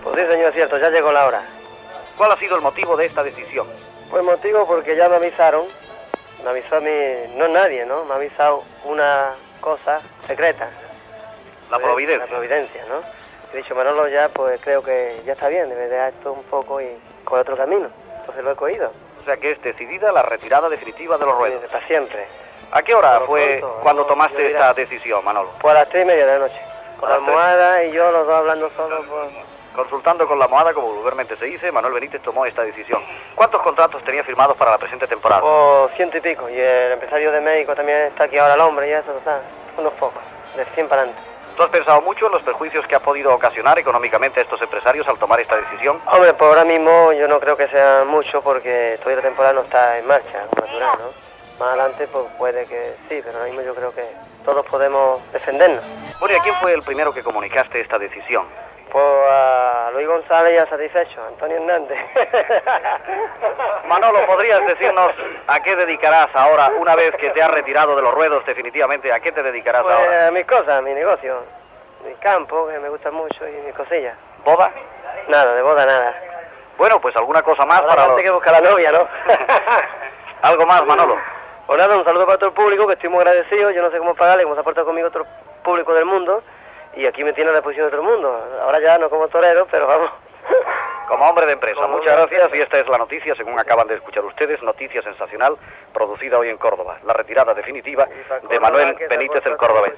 Entrevista al torero Manuel Benítez "El Cordobés", sobre la seva sobtada retirada (anys després va tornar a torejar)
Entreteniment